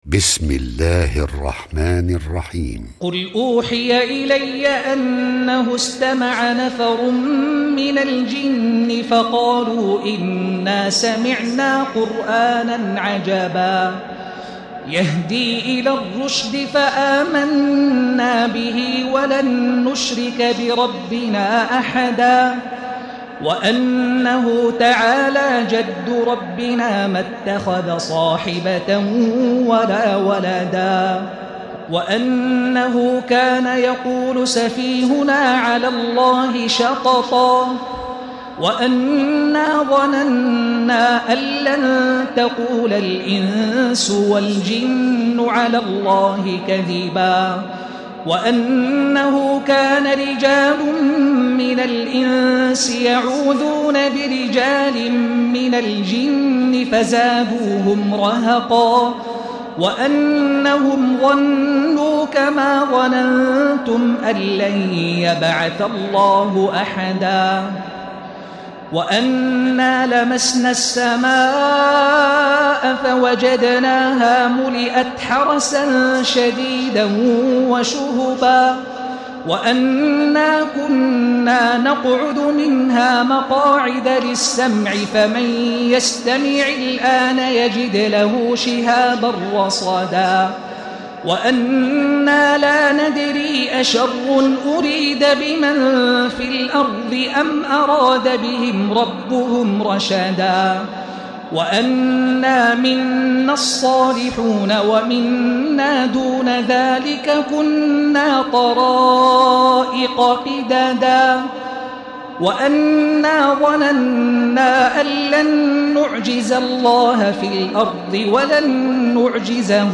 تراويح ليلة 28 رمضان 1436هـ من سورة الجن الى المرسلات Taraweeh 28 st night Ramadan 1436H from Surah Al-Jinn to Al-Mursalaat > تراويح الحرم المكي عام 1436 🕋 > التراويح - تلاوات الحرمين